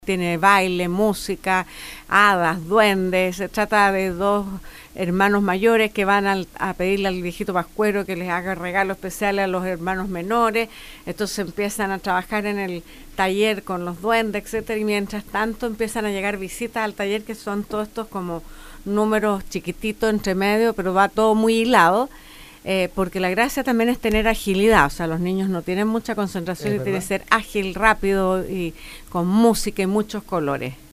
En diálogo con Radio SAGO, la Primera Dama de la Nación, Cecilia Morel, se refirió a polémica imitación del Presidente Piñera realizada por humorista Stefan Kramer en cierre de la Teletón.